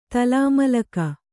♪ talāmalaka